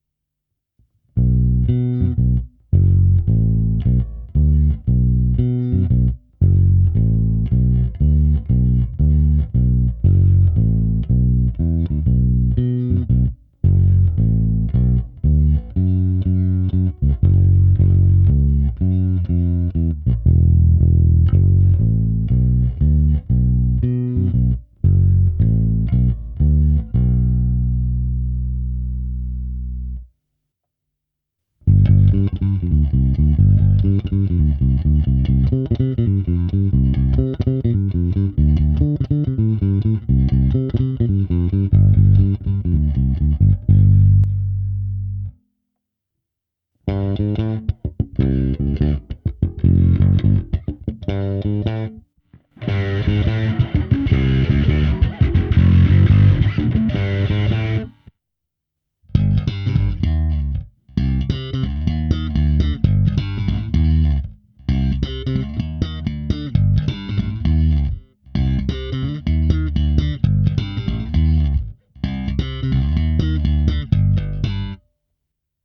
Nahrávka přes preamp Darkglass Alpha Omega Ultra se zapnutou simulací aparátu a také přes kompresor TC Electronic SpectraComp. Ukázky v pořadí: krkový snímač, oba snímače, kobylkový snímač bez a se zkreslením, slap na oba snímače.